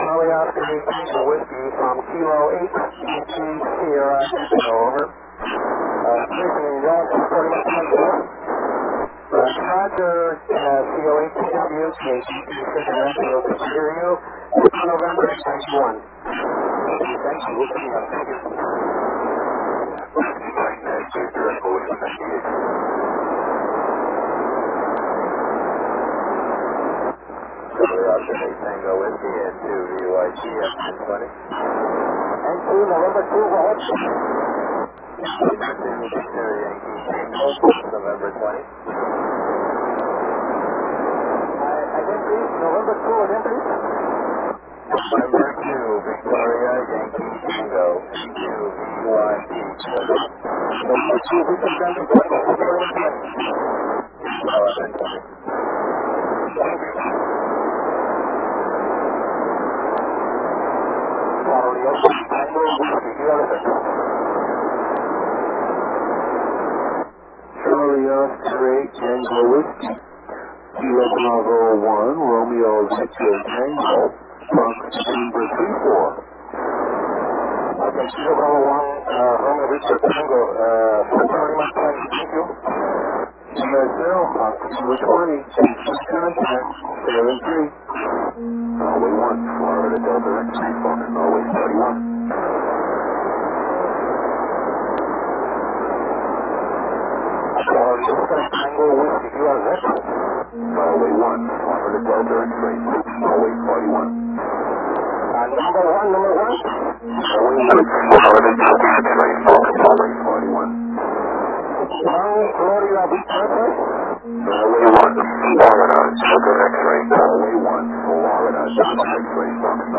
Audio and video of some qso
SO-67 Satellite 10/Apr/2011 at 00.54 utc 145/435 Mhz band on FM. -- Audio. (125 seconds)